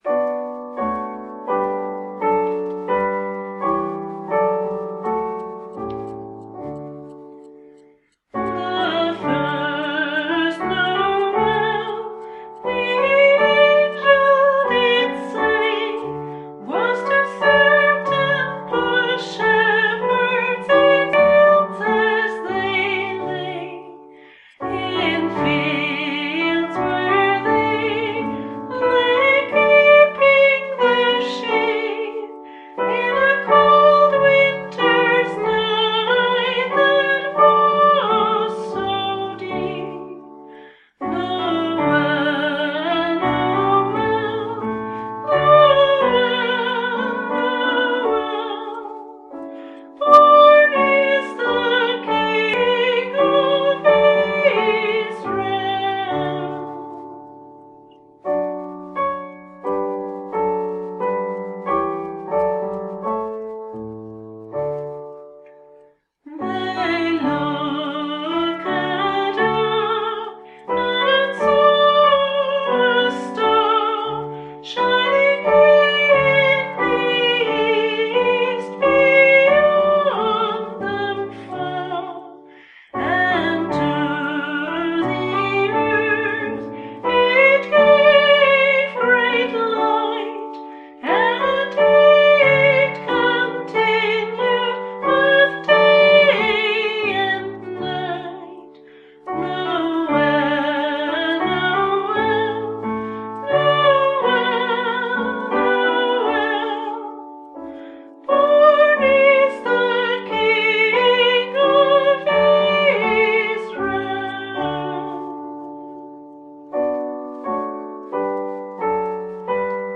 voice
piano
Words: Traditional English carol, 17th century
Music: THE FIRST NOWELL, English Carol